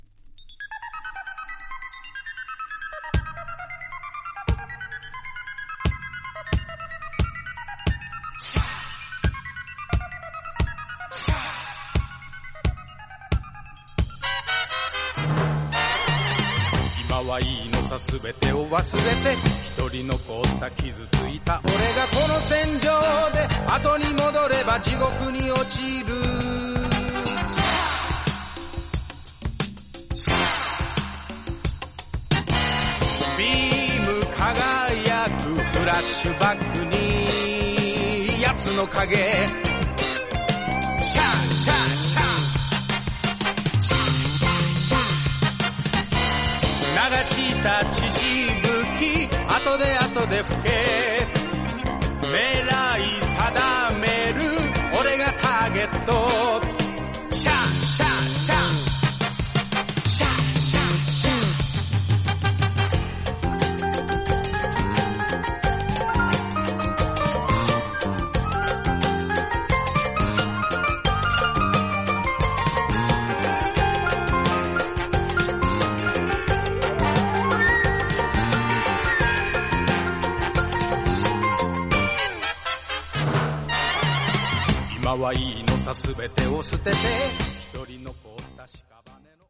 B面に薄い、短いスリキズが一本ありますが音に影響ありません。
実際のレコードからのサンプル↓ 試聴はこちら： サンプル≪mp3≫